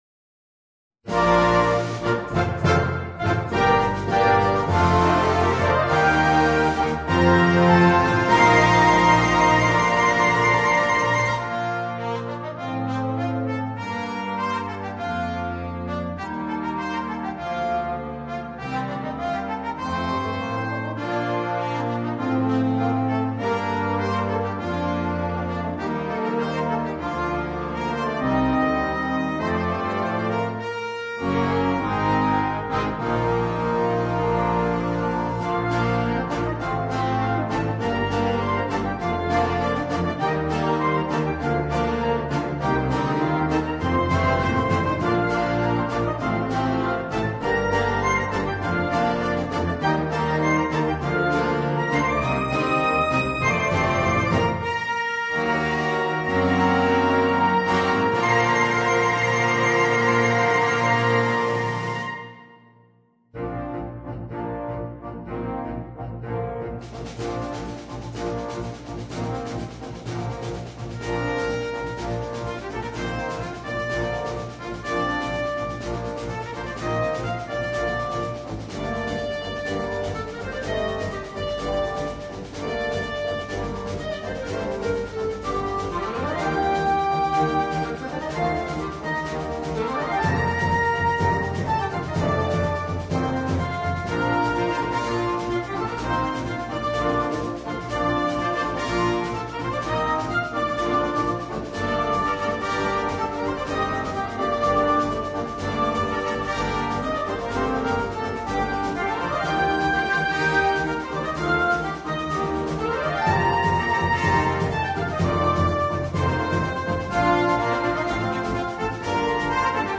MUSICA PER BANDA